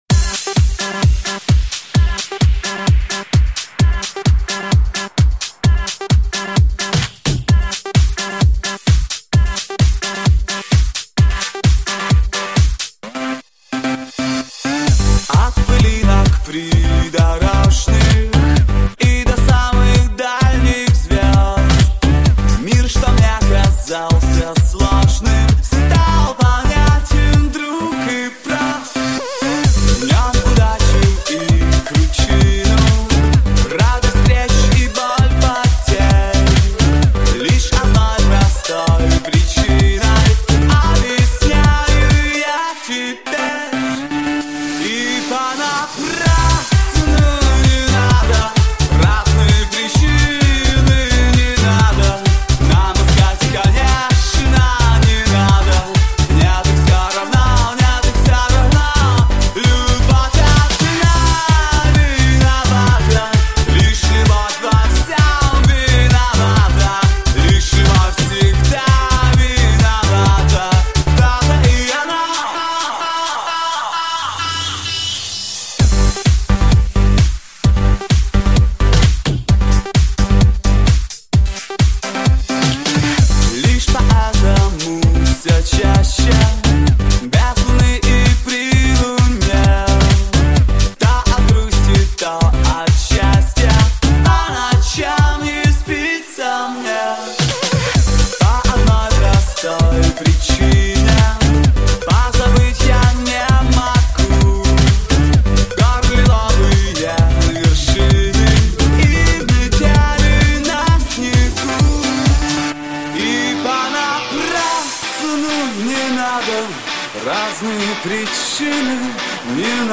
Главная » Клубная музыка